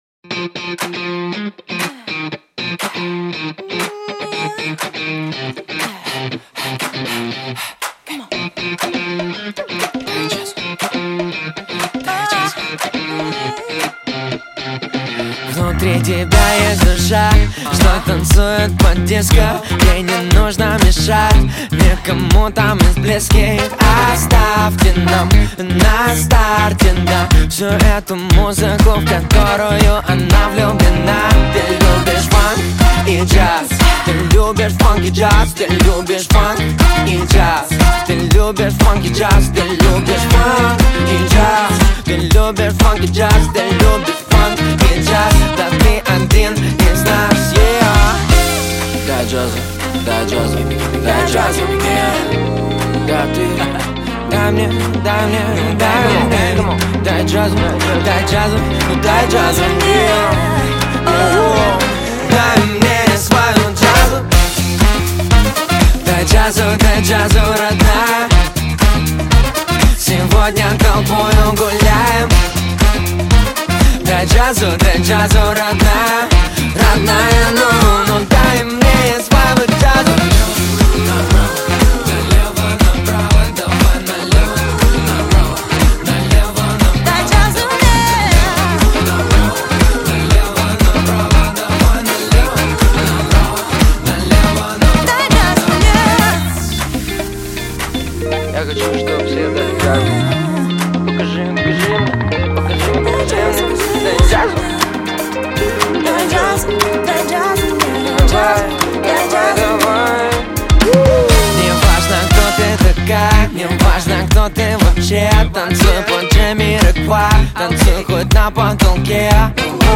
Жанр: Русский рэп / R & B